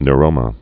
(n-rōmə, ny-)